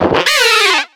Cri de Chaffreux dans Pokémon X et Y.